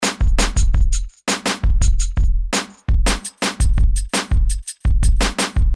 描述：果味循环
Tag: 84 bpm Rap Loops Drum Loops 492.43 KB wav Key : Unknown